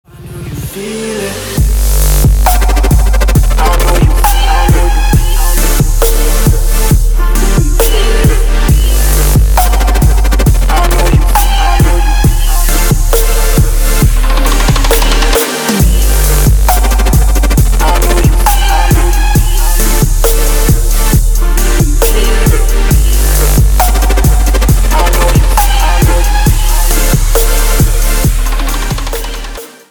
красивые
Electronic
future bass
Dubstep